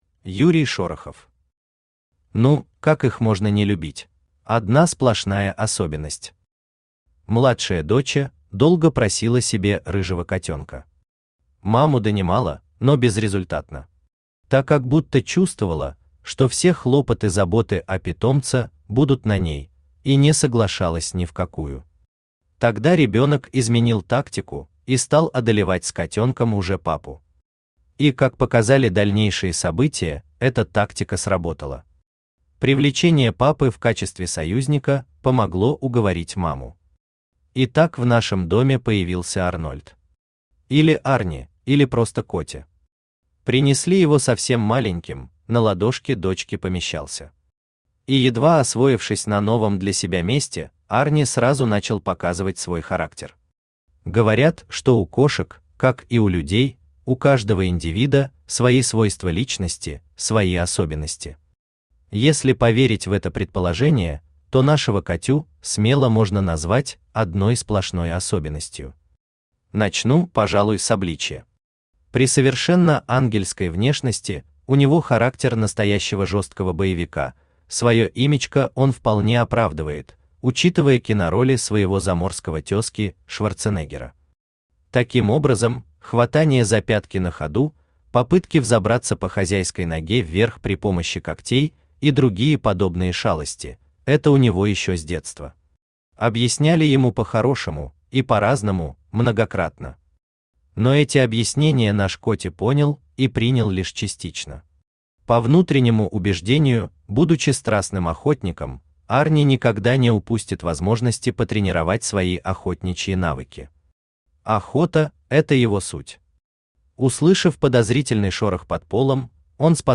Аудиокнига Ну, как их можно не любить? | Библиотека аудиокниг
Автор Юрий Шорохов Читает аудиокнигу Авточтец ЛитРес.